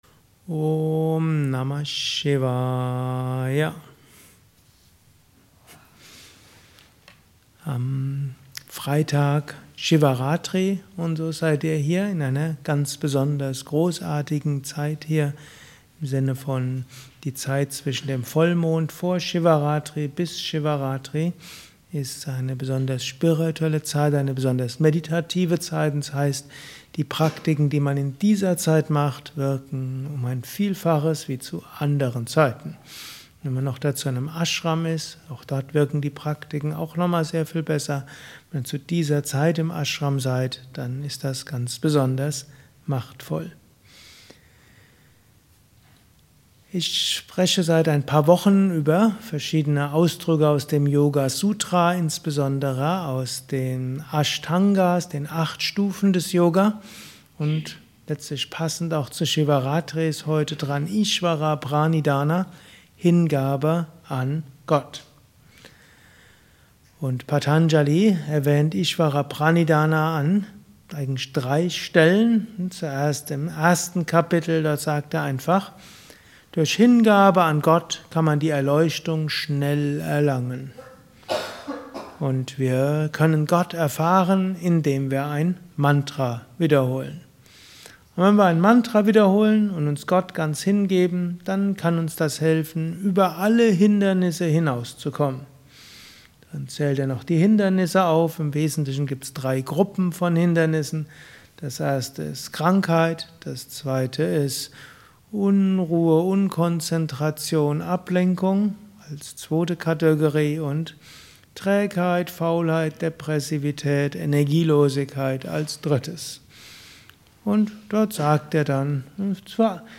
Anschluss nach einer Meditation im Haus Yoga Vidya Bad Meinberg.